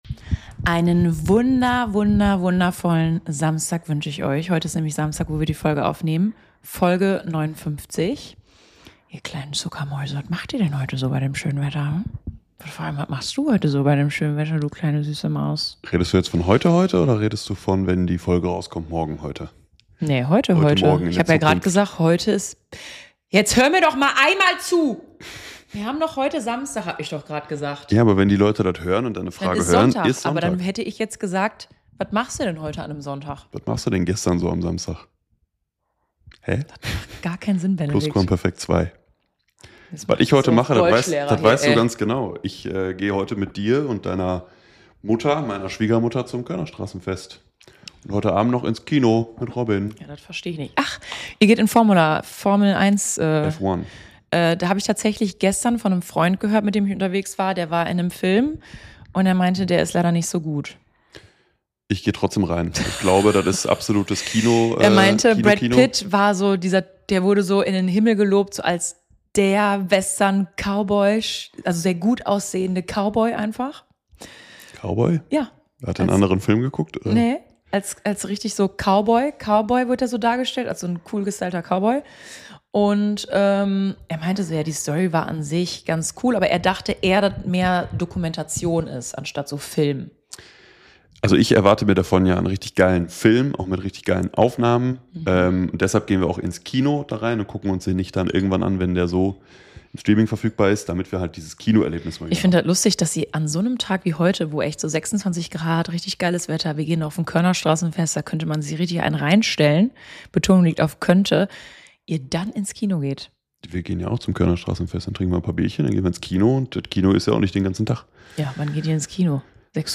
Die beiden Hosts